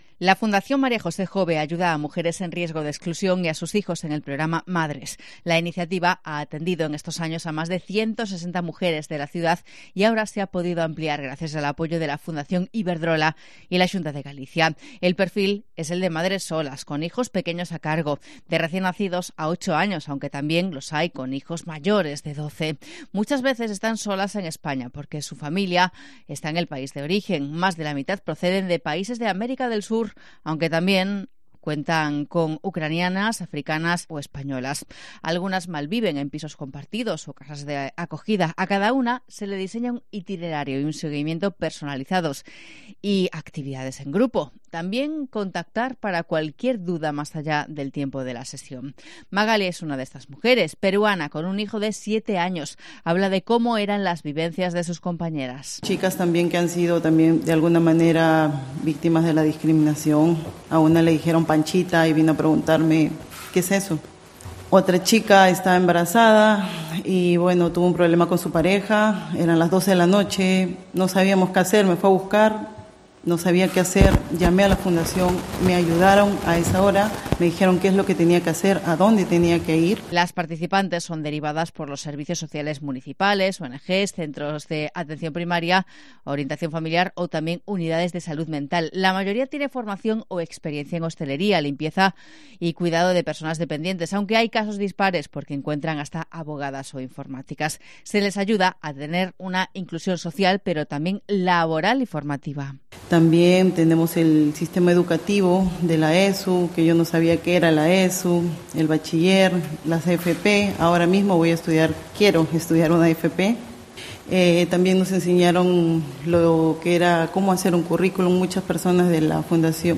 Así es el programa MADRES de la Fundación María José Jove, contado por una mujer beneficiaria